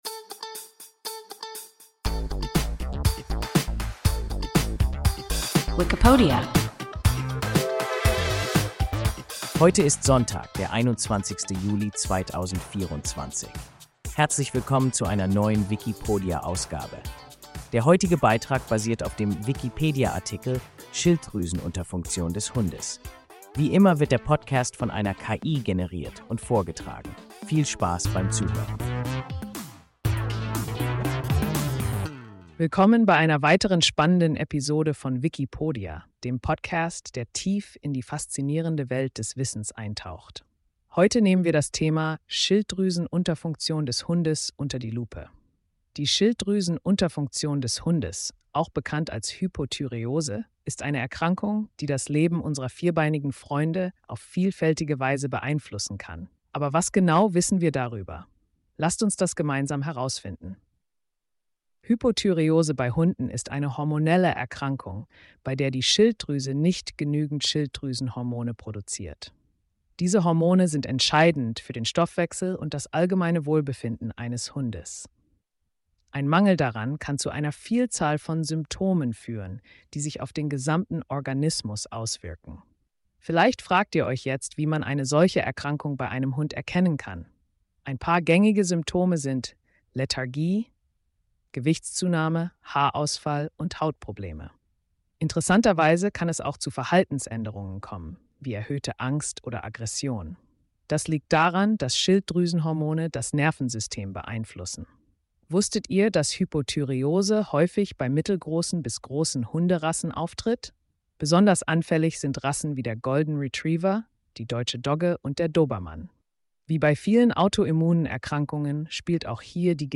Schilddrüsenunterfunktion des Hundes – WIKIPODIA – ein KI Podcast